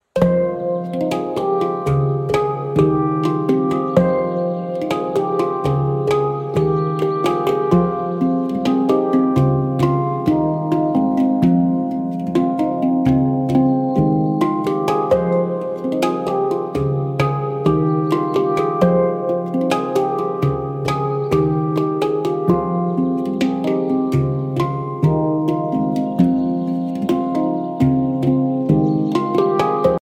Atrakcje Na Imprezy Firmowe | Drumbastic Samba Batucada Group | Warsaw
Drumbastic samba batucada group - Pokazy, koncerty, doping na imprezach sportowych -energetyczne brazylijskie show perkusyjne w wykonaniu znakomitych bębniarzy!